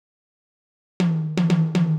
120 BPM Beat Loops Download